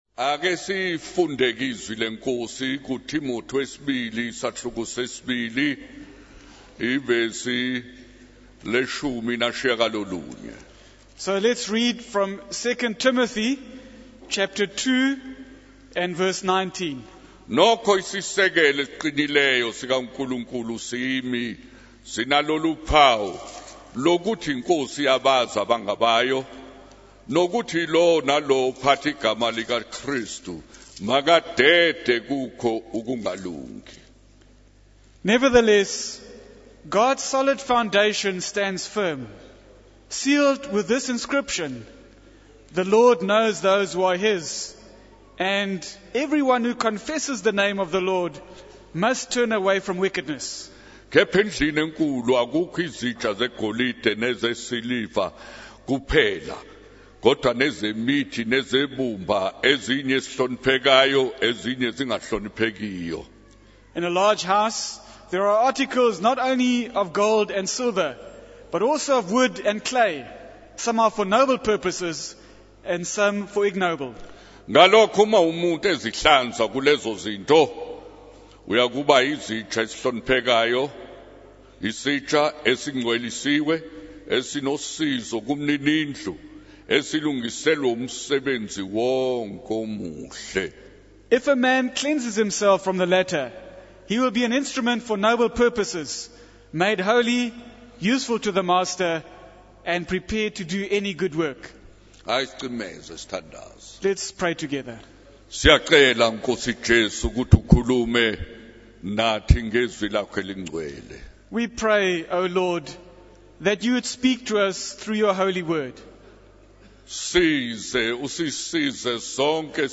In this sermon, the preacher emphasizes the power of the gospel in raising people up.